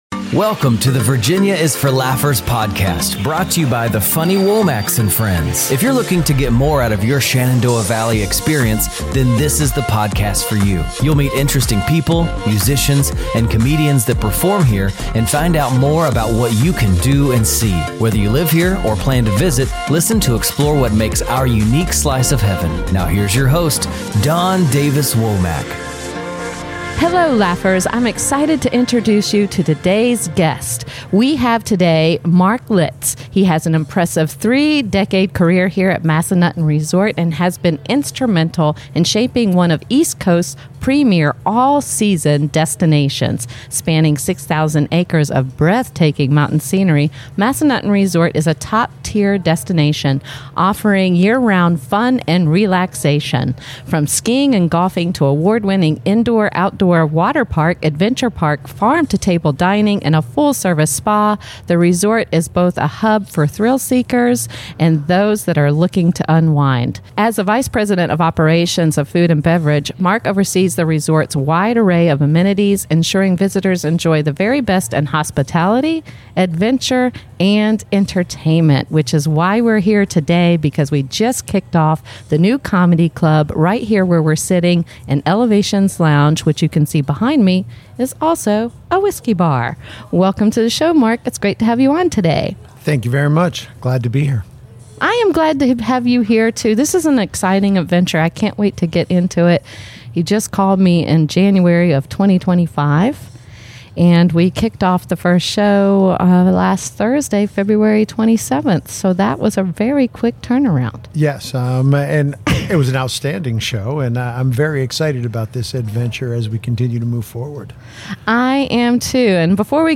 1 Entrevista Radio del Plata